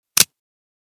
bm16_unjam.ogg